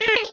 確かに 442 [Hz] の音のような気がしますが、波形と STFT をかけた結果を確認しましょう。
途中 (0.15 秒あたり) すごく小さくなってますね。
やはり0.15 秒周辺にノイズっぽいものが見えますが、総じて 442[Hz] 周辺が強く出せました。